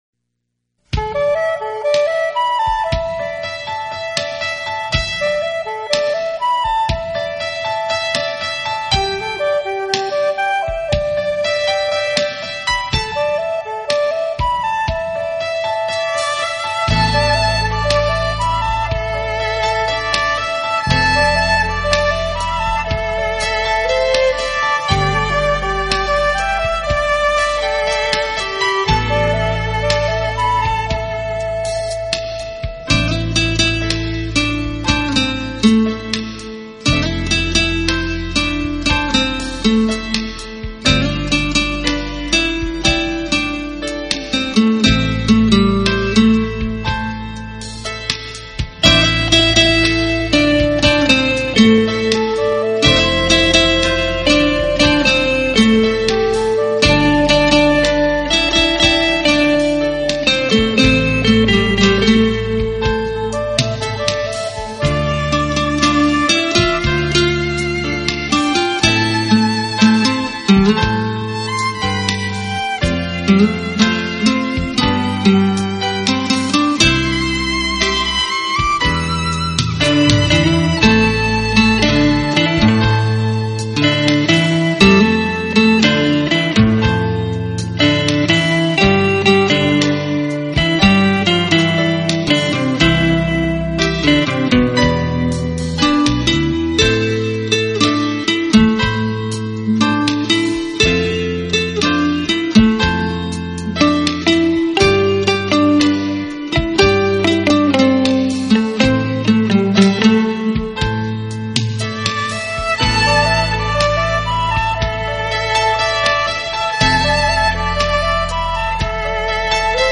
专辑音色清脆动人且温馨旖丽，不禁展示了精彩绝伦的空间感，而且带出吉他
音箱共鸣声的无限通透。
用吉他的清脆表现大师音乐的干净、深度和静谧美丽得让人心碎的旋律。